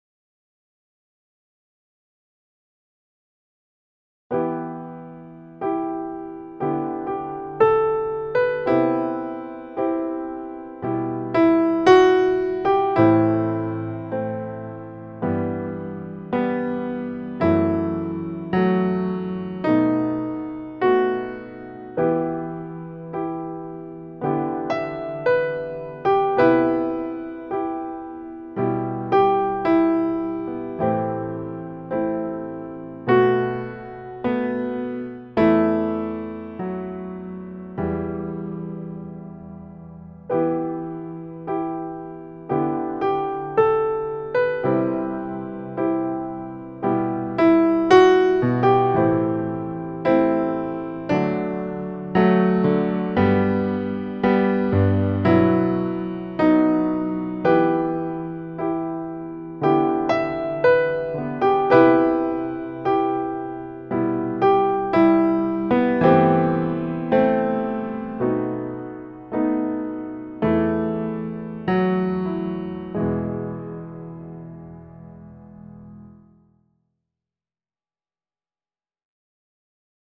piano1.m4a